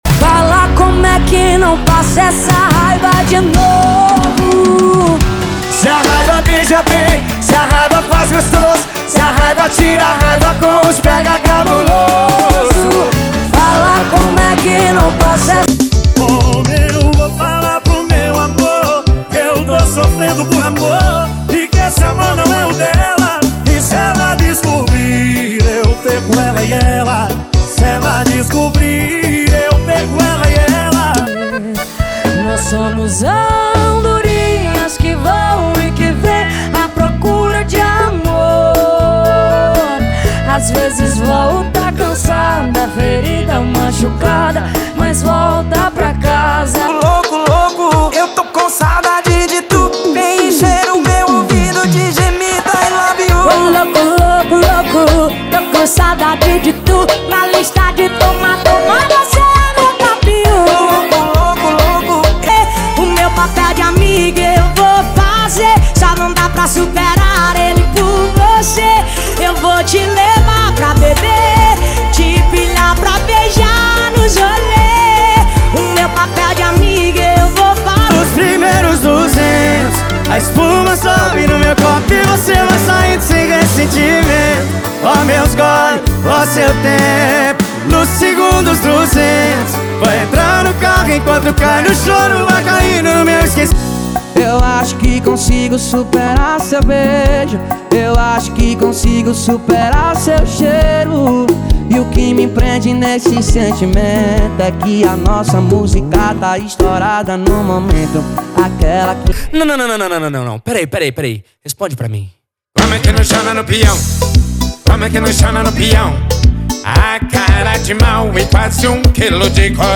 • Sertanejo = 50 Músicas
• Sem Vinhetas